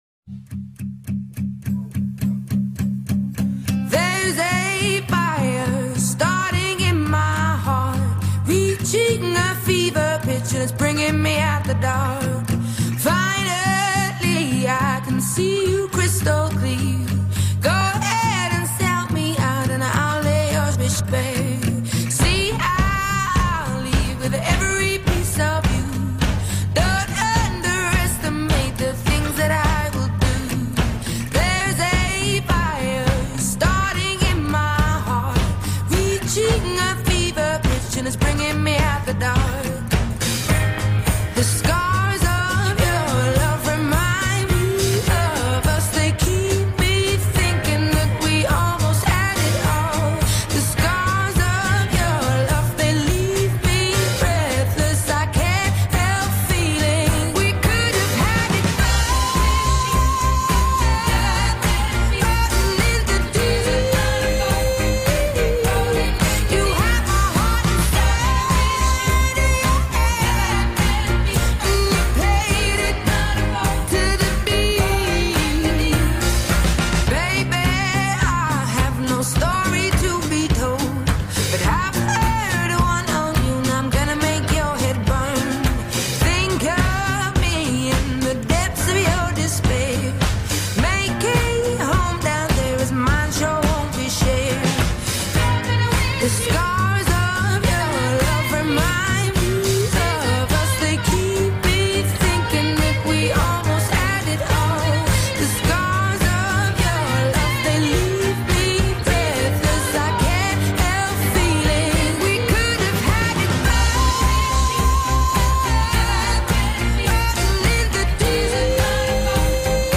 TO HEAR THE SONG, CLICK HERE Apologies for the female voice.